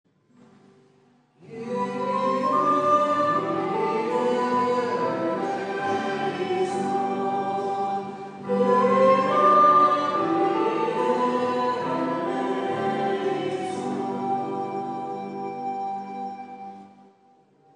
Pregària de Taizé
Església de Santa Anna - Diumenge 26 d'octubre de 2014